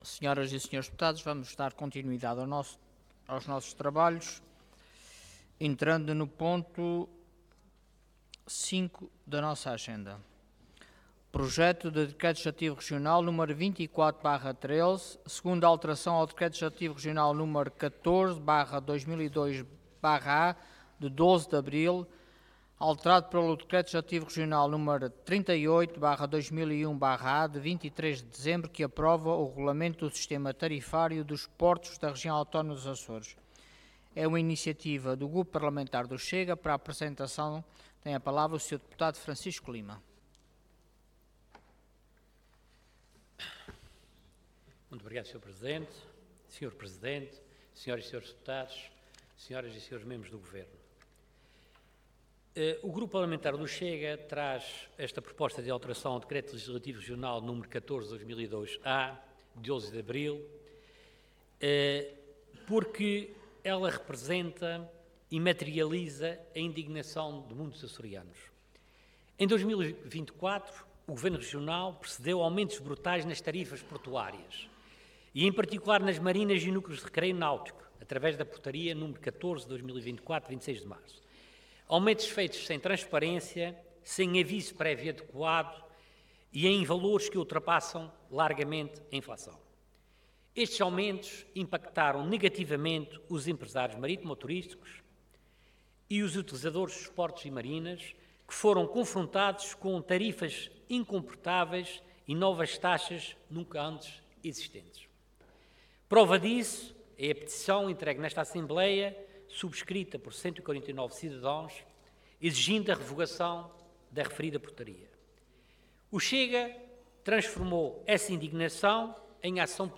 Intervenção